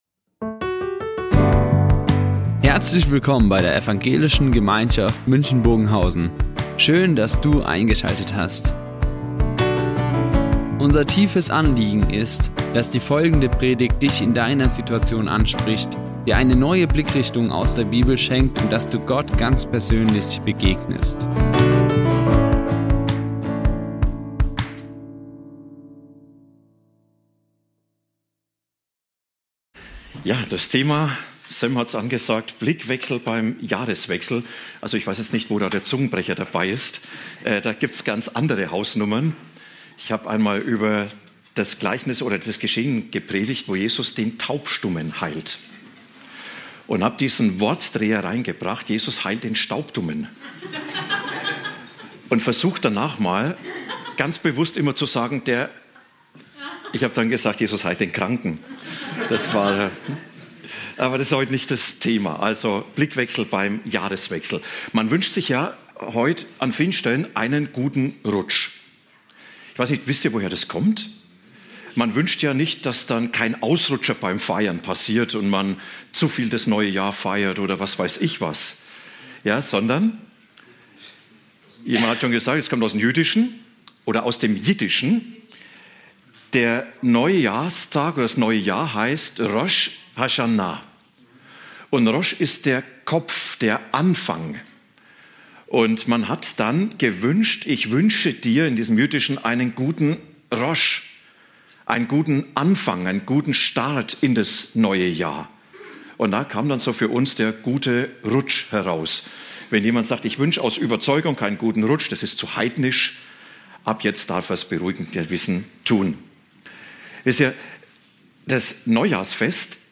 Blickwechsel beim Jahreswechsel | Predigt Philipper 4, 4-7 ~ Ev.